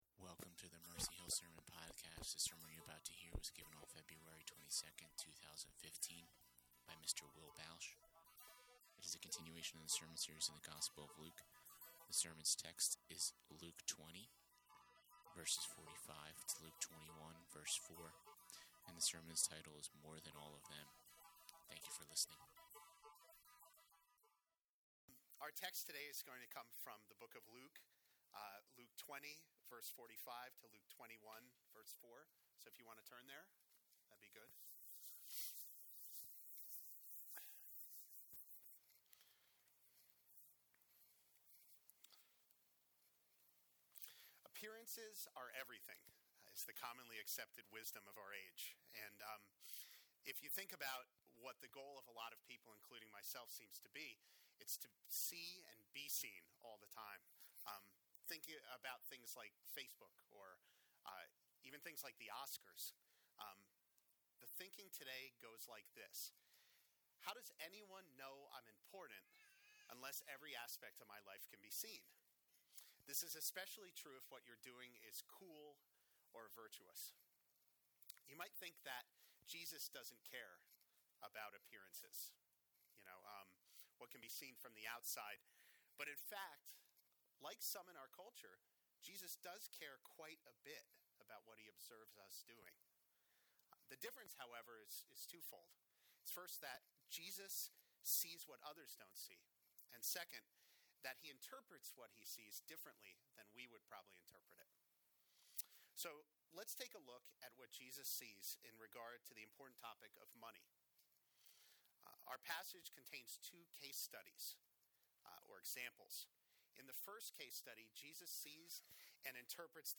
More than all of them - Mercy Hill Presbyterian Sermons - Mercy Hill NJ